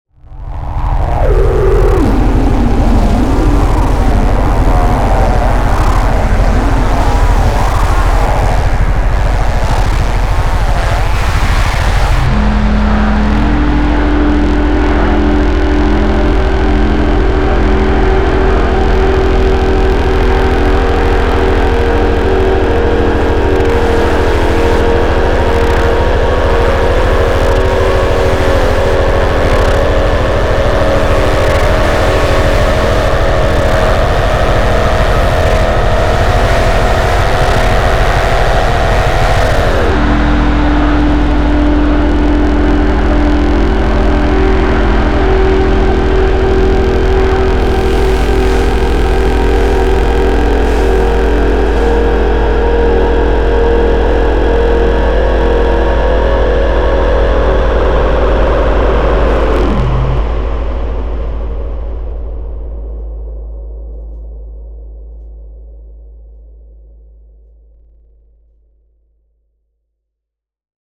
Modular / Fors Ego+Romb